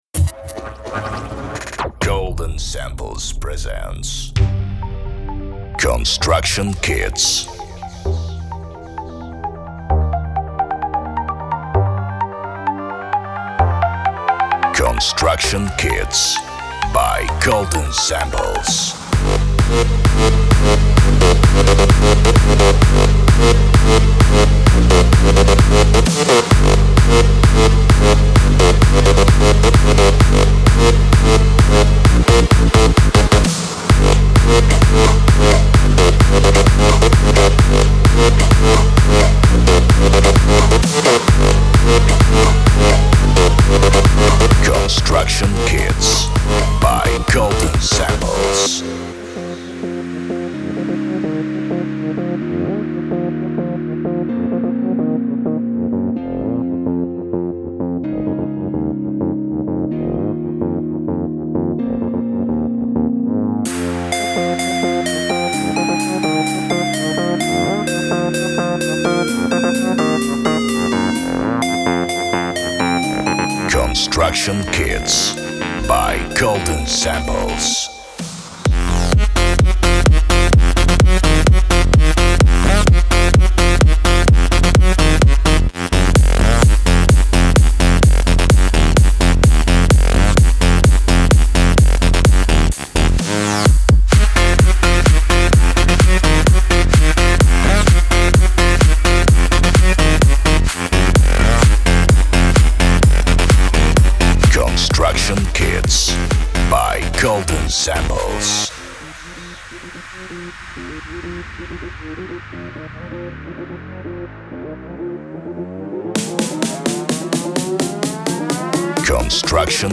所有音乐均以高质量的24位/44.1kHz记录。
• 128 BPM Black F maj
• 128-130 BPM
• Big Room Styles